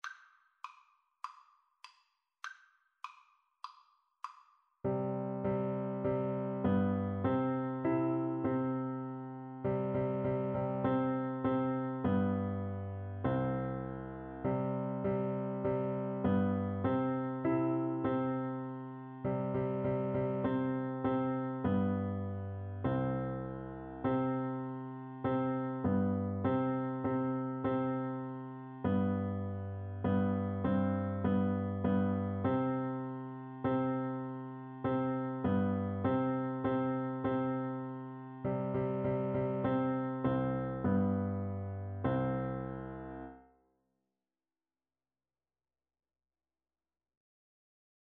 Free Sheet music for Piano Four Hands (Piano Duet)
4/4 (View more 4/4 Music)